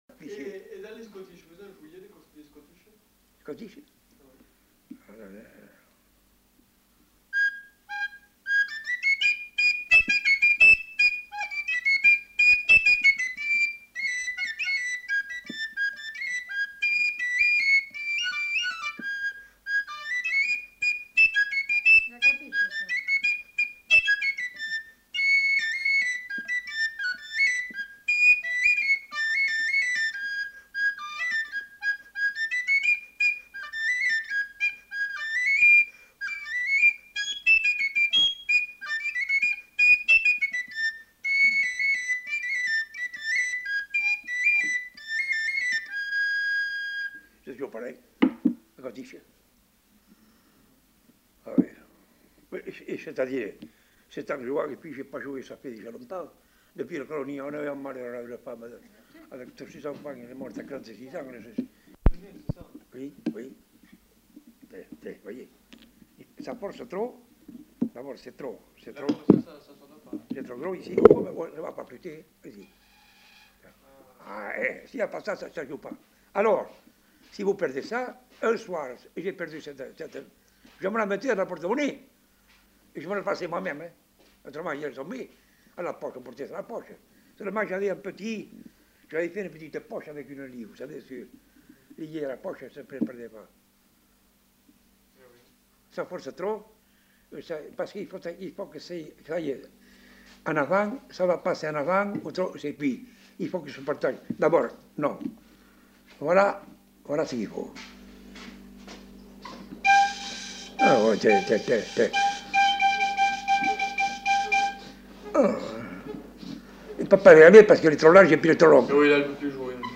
Aire culturelle : Bazadais
Genre : morceau instrumental
Instrument de musique : flûte à trois trous
Danse : scottish
L'interprète joue deux airs différents.